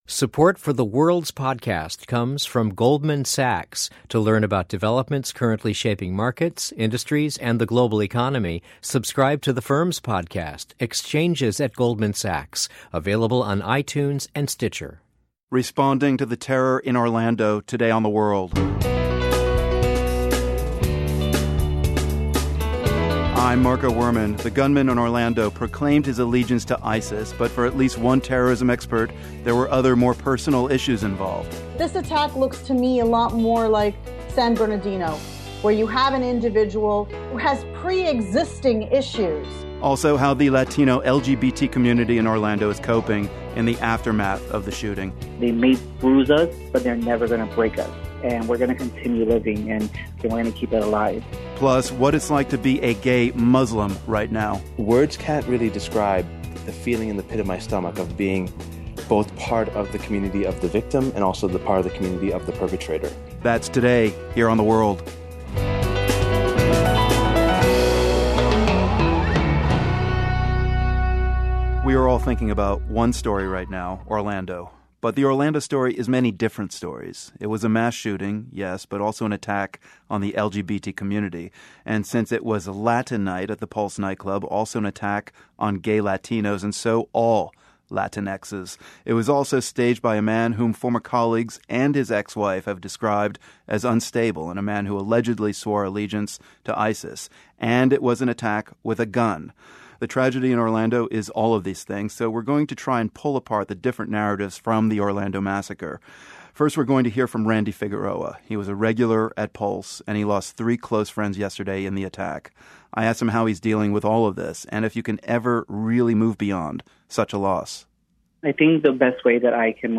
We hear from members of Orlando's LGBT community about Pulse nightclub, and why it's been a safe space for many people there. Plus, a gay Muslim man in Toronto tells how the attack on Sunday took aim at both sides of his life.